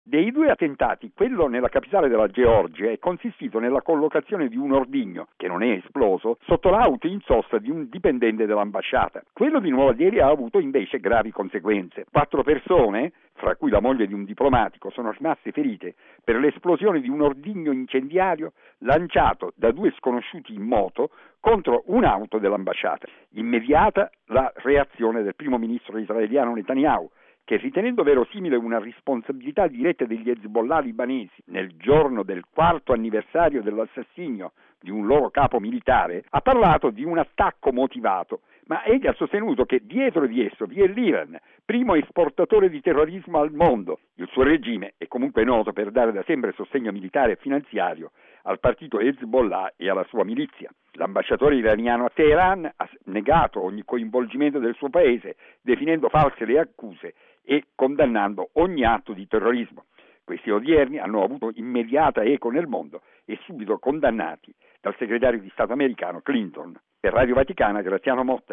Il servizio